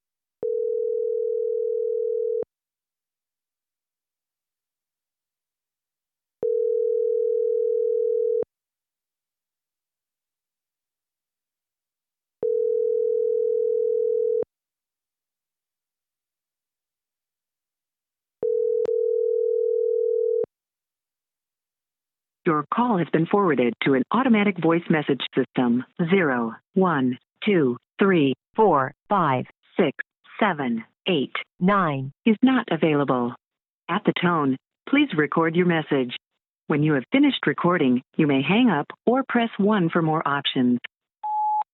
call going to voicemail
answering answering-machine call machine phone voicemail your sound effect free sound royalty free Memes